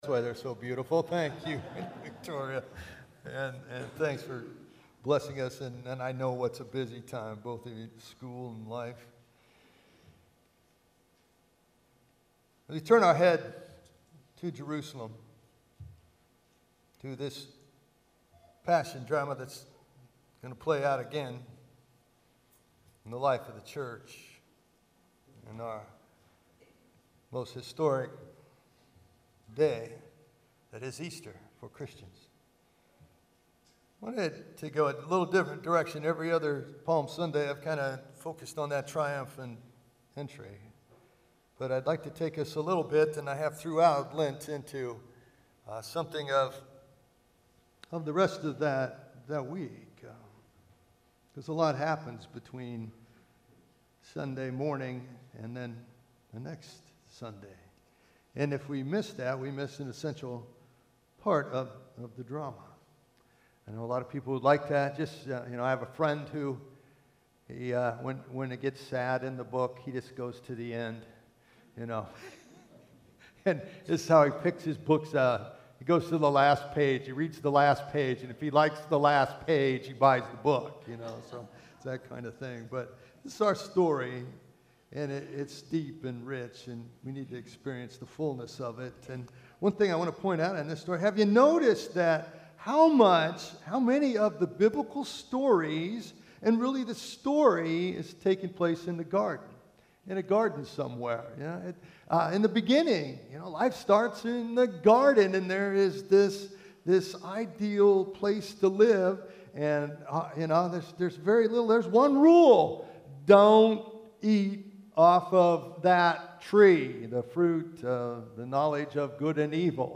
Palm Sunday. In this season leading up to Easter, we learn to Love God, Love each Other, and Change the World.